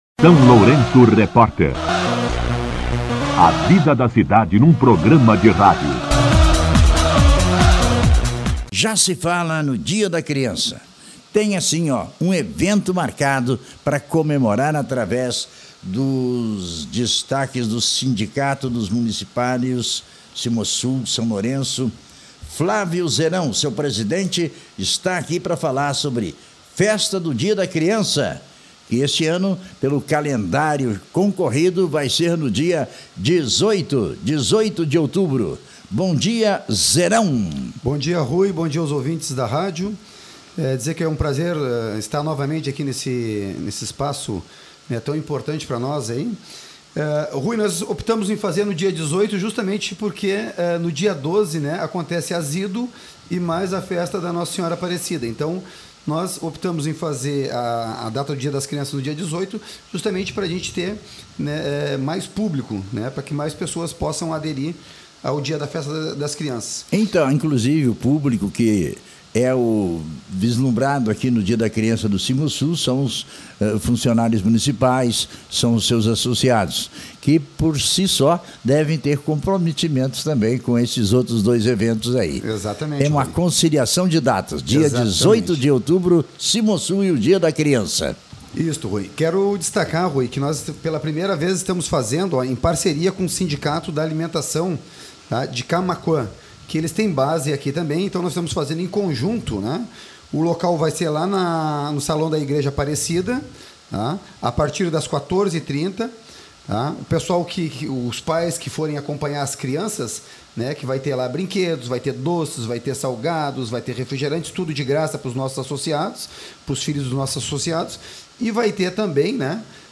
esteve no SLR RÁDIO para convidar a comunidade para um grande evento comemorativo do Dia das Crianças.